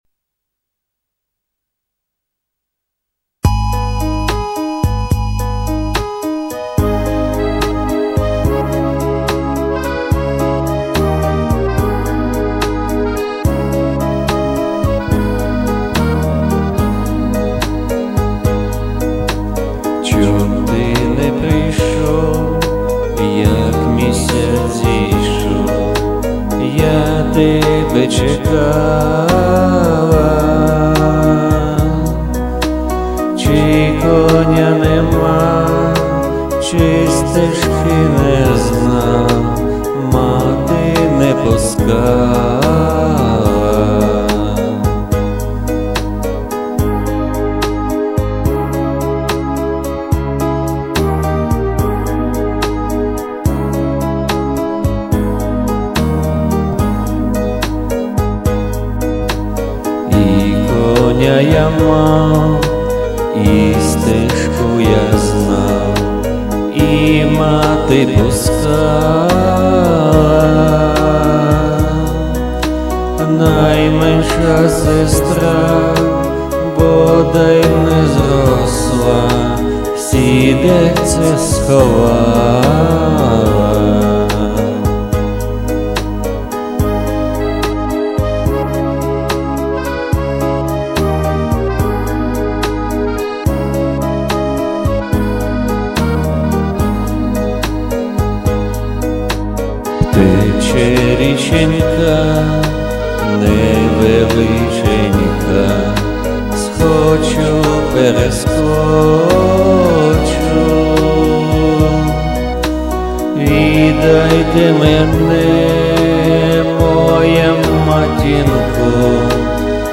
Украинская народная песня"Чом ти не прийшов"в собственном исполнении,прошу оценить.
Музыкальное сопровождение слегка "приглушить" по уровню громкости. А иначе забивает голос.